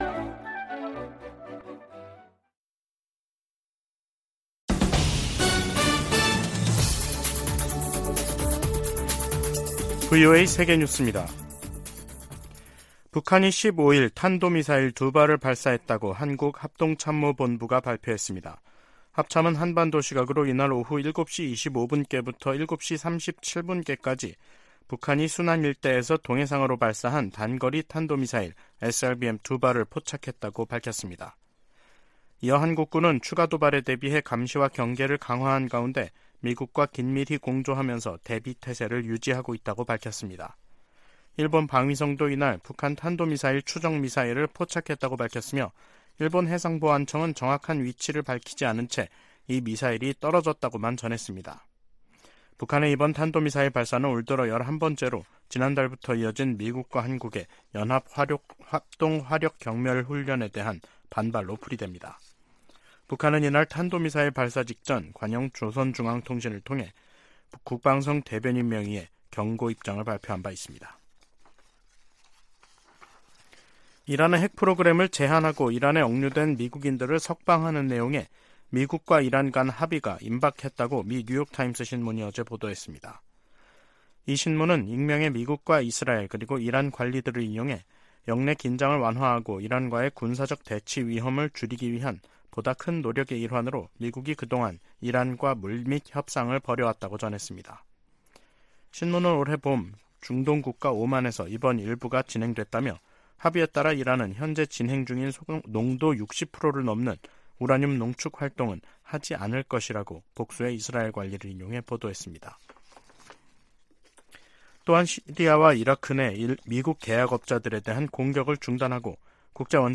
VOA 한국어 간판 뉴스 프로그램 '뉴스 투데이', 2023년 6월 15일 2부 방송입니다. 미국은 심해지는 중국과의 경쟁을 관리하기 위해 한국, 일본 등과 동맹을 강화하고 있다고 미국 정부 고위 관리들이 밝혔습니다. 미국 하원 세출위원회 국방 소위원회는 2024회계연도 예산안에서 미군 유해 수습과 신원 확인 외에는 어떤 대북 관련 지원도 할수 없도록 했습니다.